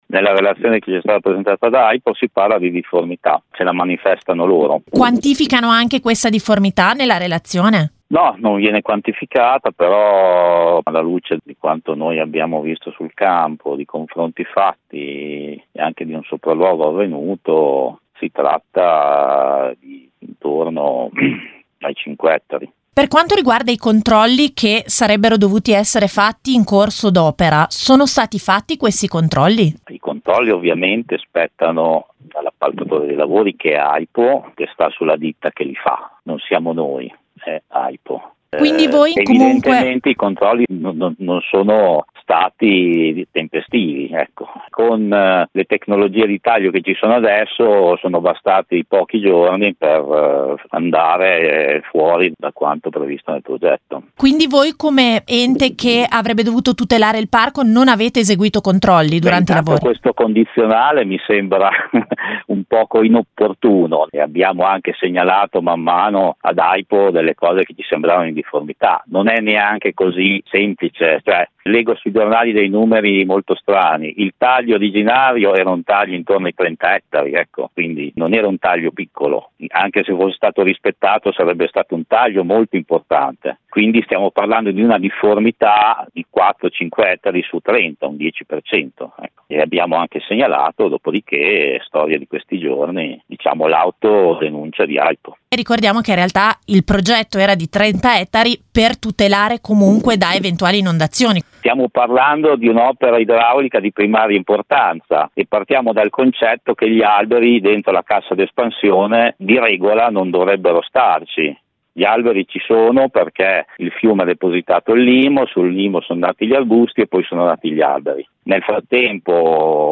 ha intervistato